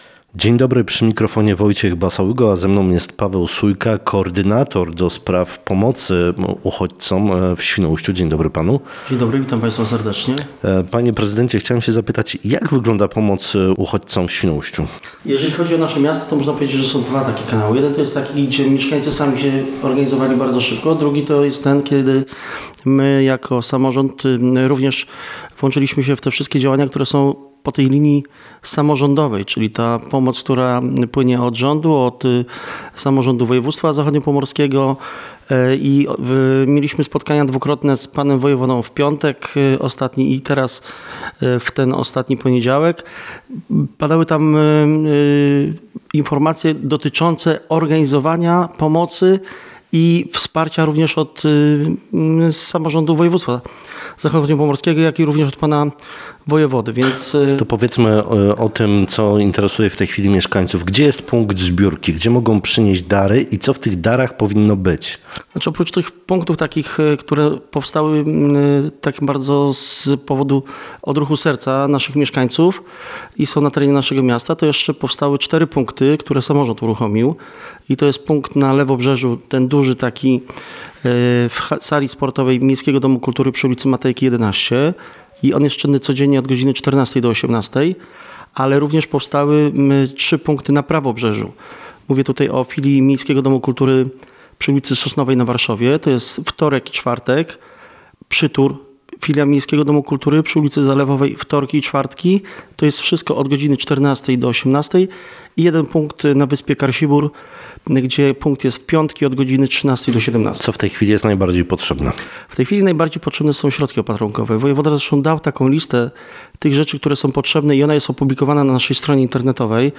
Naszego gościa zapytaliśmy między innymi o to, jak wygląda pomoc dla uchodźców z Ukrainy, która prowadzona jest w Świnoujściu.